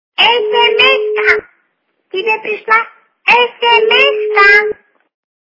» Звуки » звуки для СМС » Звонок для СМС - Тебе СМС-ка!
При прослушивании Звонок для СМС - Тебе СМС-ка! качество понижено и присутствуют гудки.